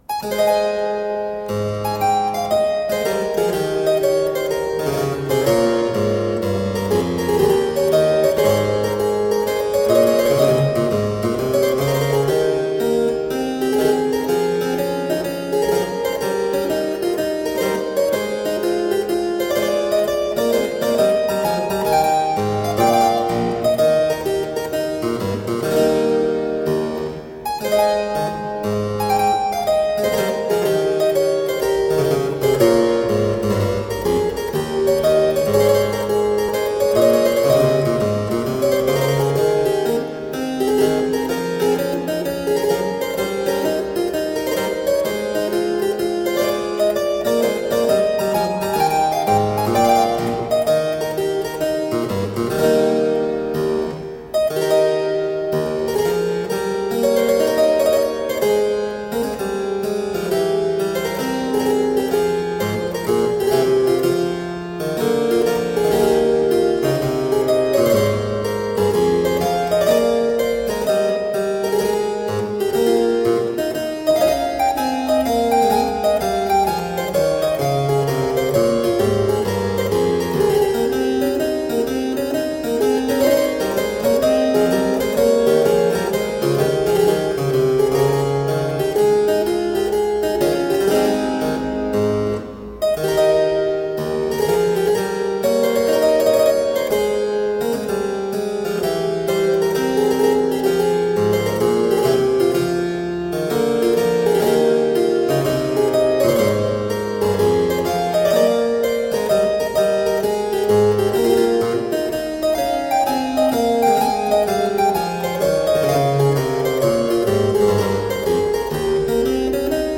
Solo harpsichord music.
Classical, Baroque, Instrumental
Harpsichord